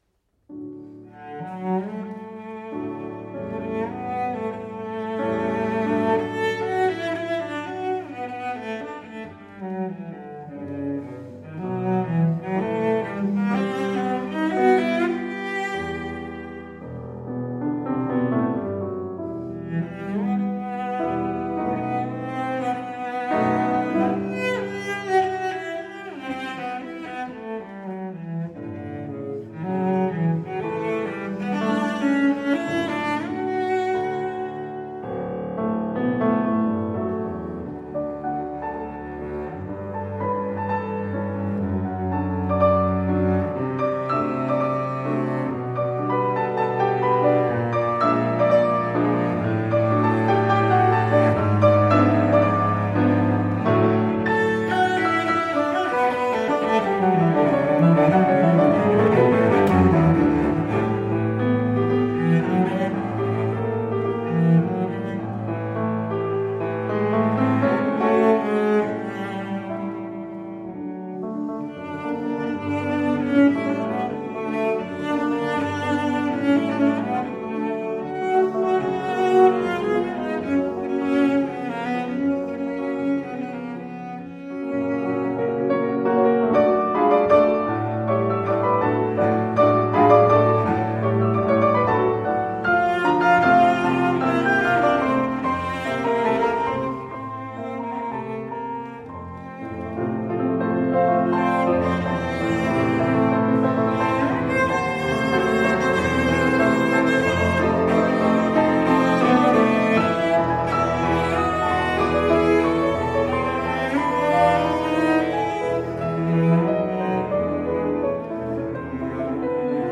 Instrument: Cello
Style: Classical
cello-sonata-1-op-18.mp3